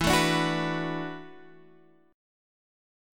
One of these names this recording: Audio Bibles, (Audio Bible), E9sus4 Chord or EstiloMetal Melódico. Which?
E9sus4 Chord